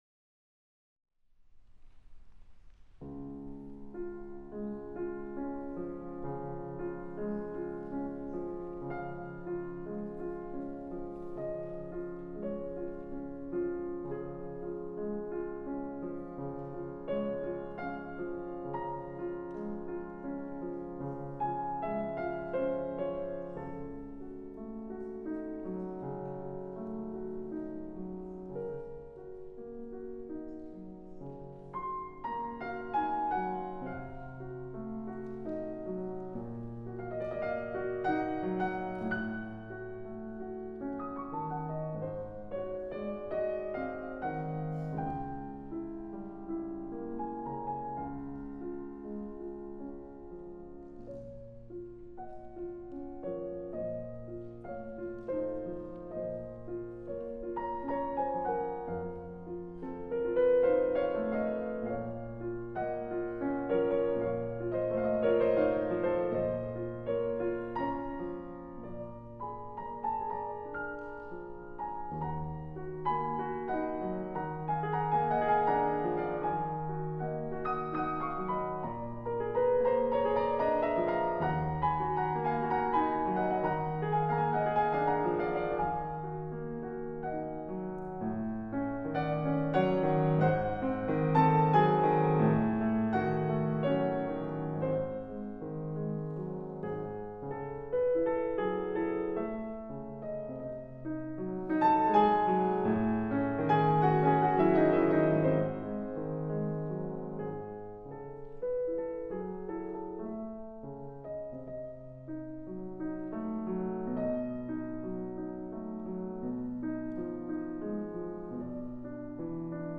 Chopin Nocturne Op.27 No.2 Recital at Bunka Kaikan, Tokyo on April 3, 2010.